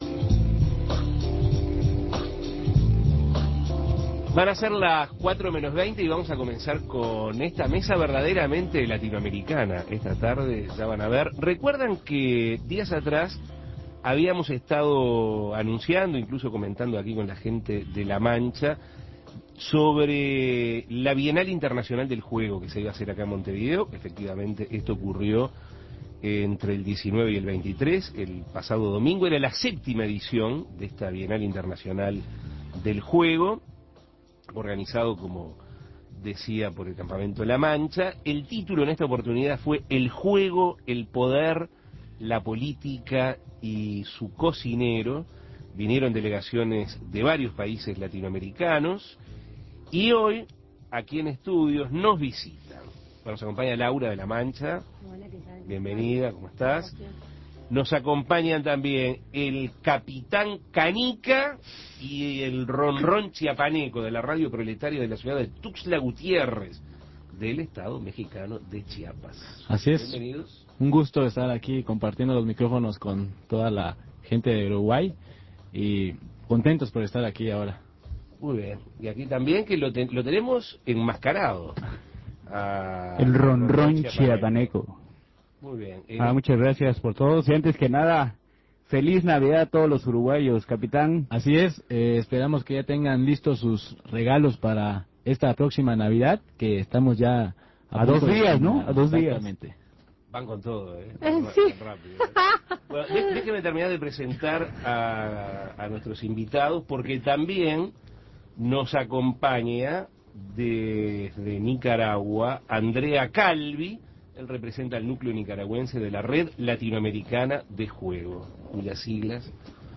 Delegaciones de varios países latinoamericanos concurrieron a la séptima edición de la Bienal Internacional del Juego, que se desarrolló en Montevideo del 19 al 23 de setiembre, en la sede del Colegio Elbio Fernández. Representantes de México y Nicaragua, junto a una integrante del centro La Mancha, de Uruguay, contaron qué les dejó esta curiosa experiencia.